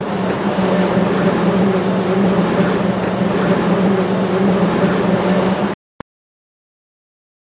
Monorail, Disneyland, Loop From Peak Of By